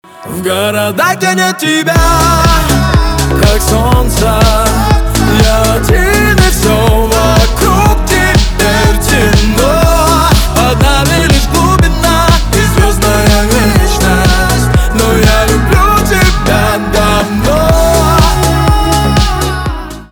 поп
романтические , чувственные
битовые , басы